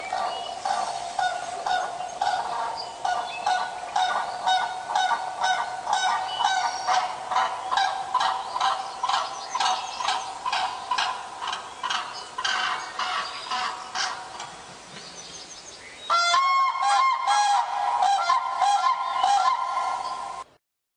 灰鹤叫声